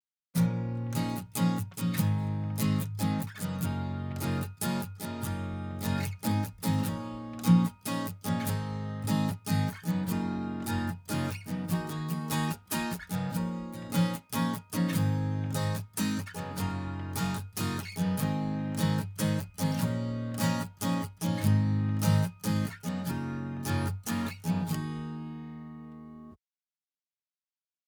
HPF 50 Hz
LF boost 70 Hz
HF boost 12 kHz
LMF Narrow Cut (FOCUS IN) at 600 Hz
HMF Wide Boost (FOCUS OUT) at 9 kHz
UV-EQ Acoustic Guitar 2.wav